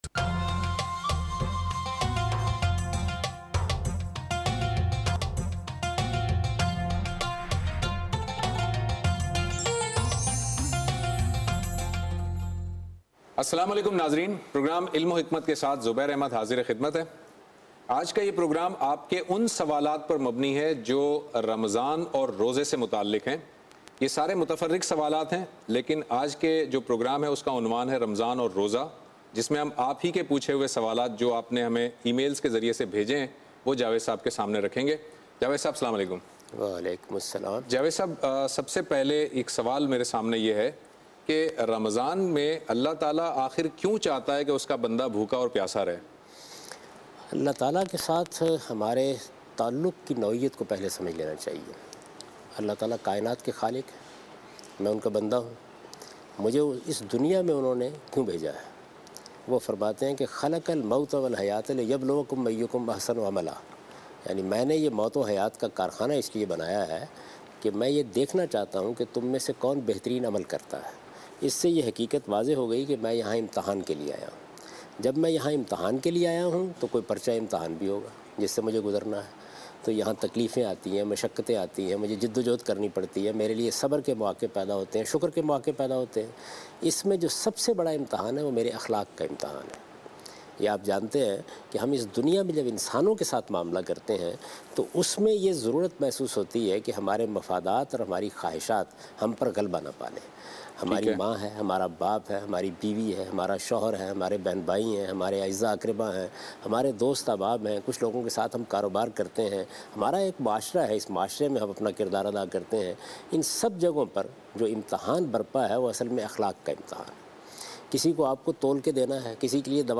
In this program Javed Ahmad Ghamidi answers the question about "Ruling about Fasting" in program Ilm o Hikmat on Dunya News.